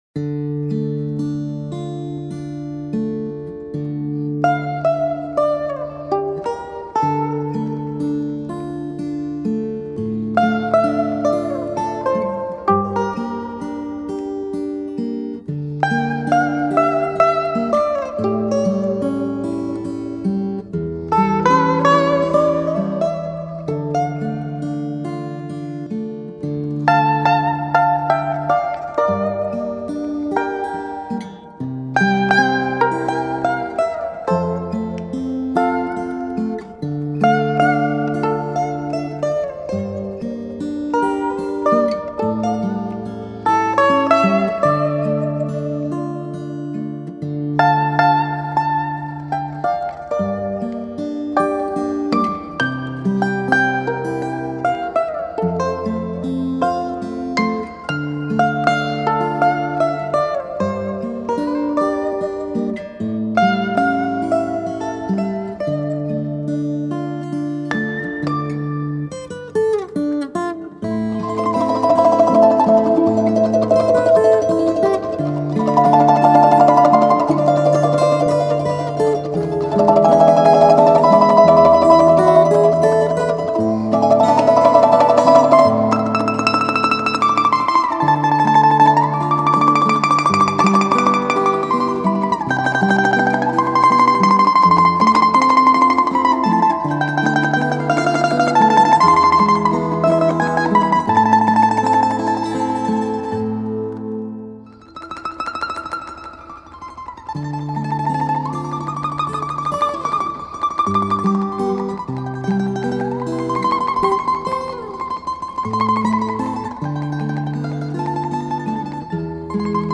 Балалайка